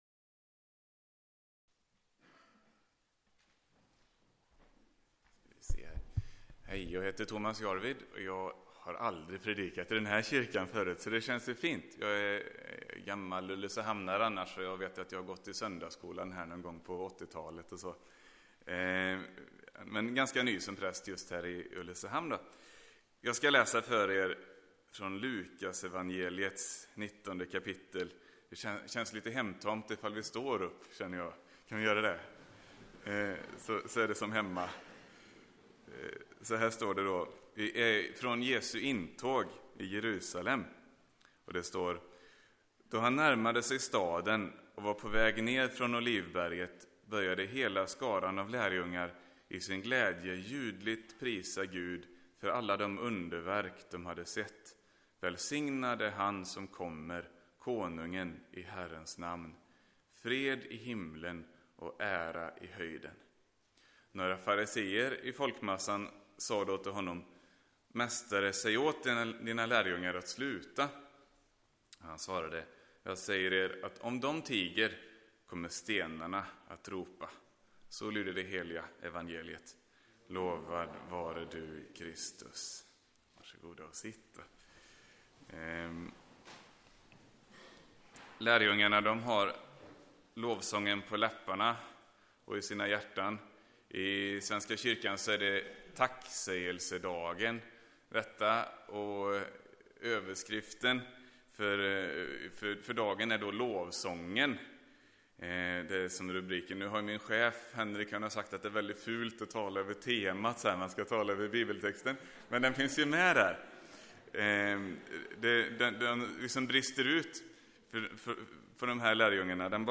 Predikar på ekumenisk gudstjänst 8 okt